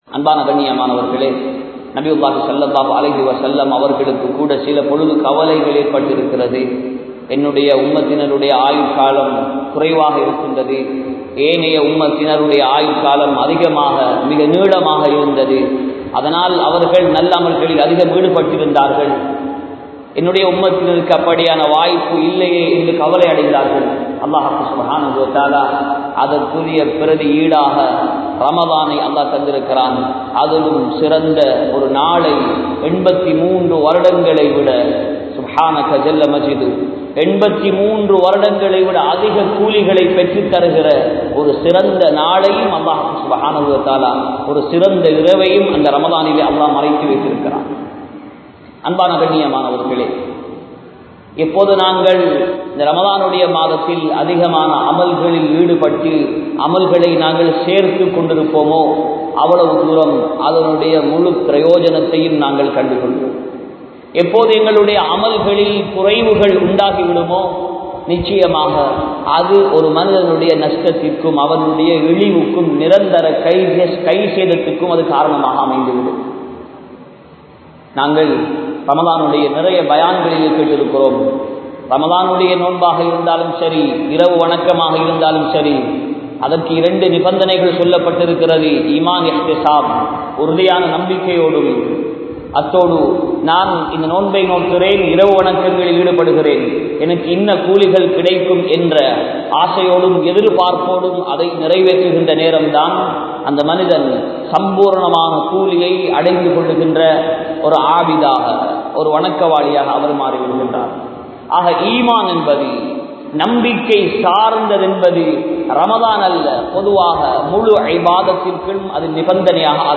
Meezanai Kanamaakkuvoam (மீஸானை கனமாக்குவோம்) | Audio Bayans | All Ceylon Muslim Youth Community | Addalaichenai
Kabeer Jumua Masjith